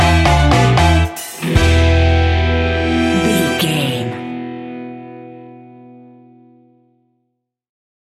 Classic reggae music with that skank bounce reggae feeling.
Aeolian/Minor
laid back
chilled
off beat
drums
skank guitar
hammond organ
percussion
horns